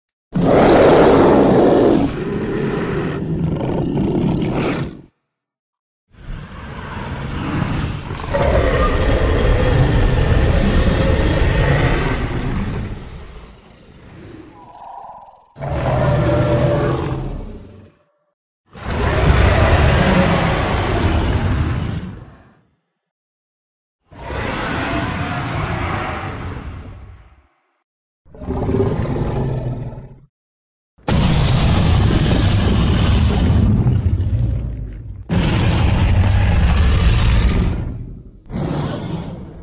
dinosaur sound ringtone free download
Sound Effects